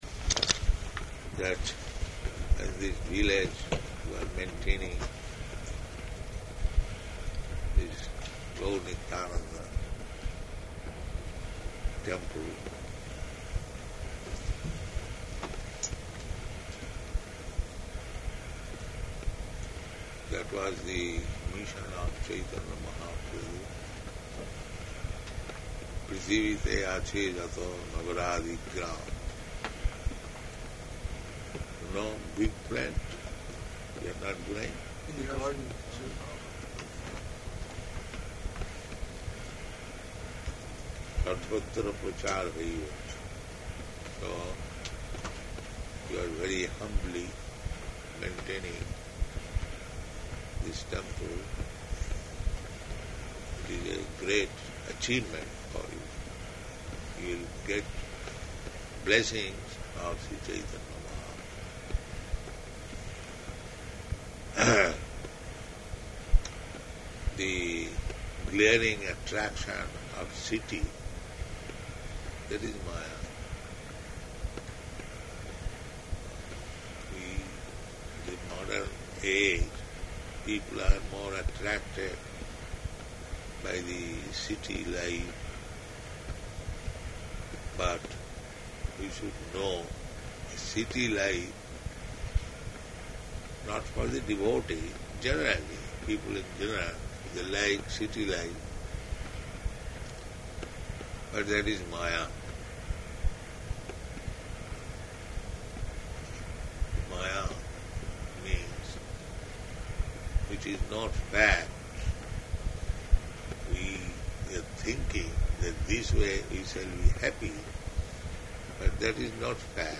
Type: Lectures and Addresses
Location: Honolulu